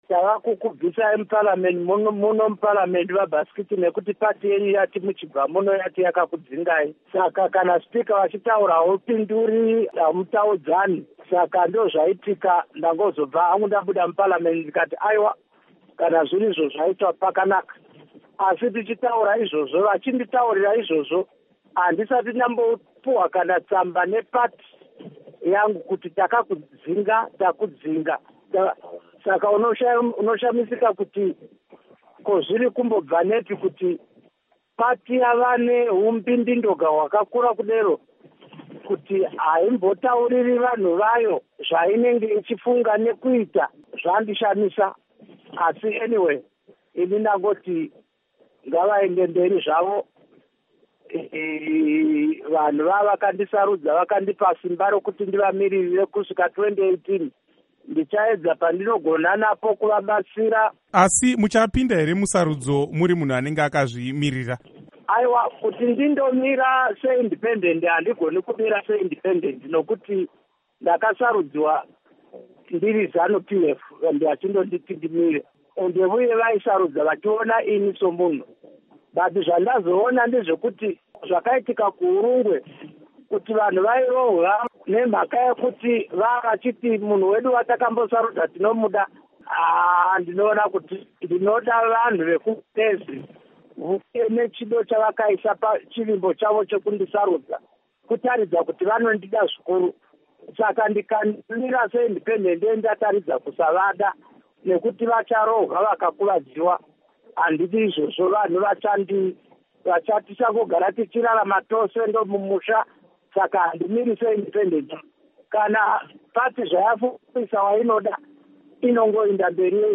Hurukuro naVaKudakwashe Bhasikiti